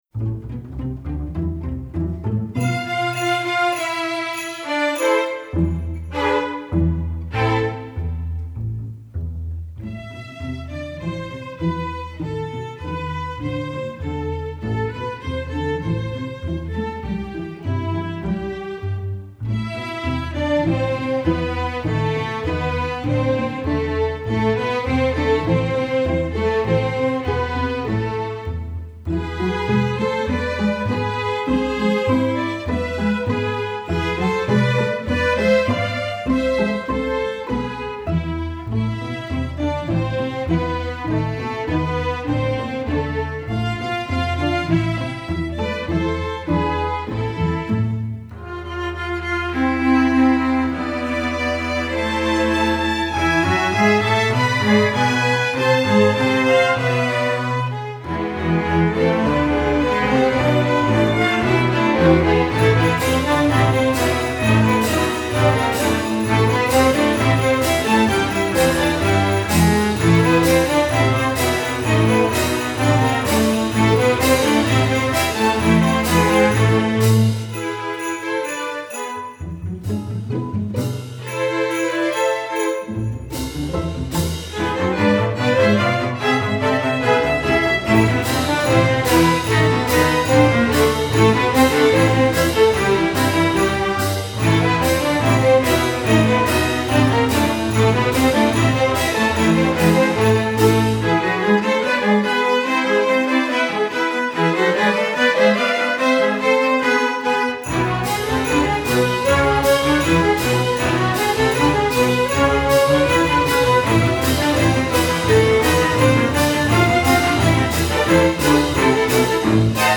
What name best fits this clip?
masterwork arrangement, secular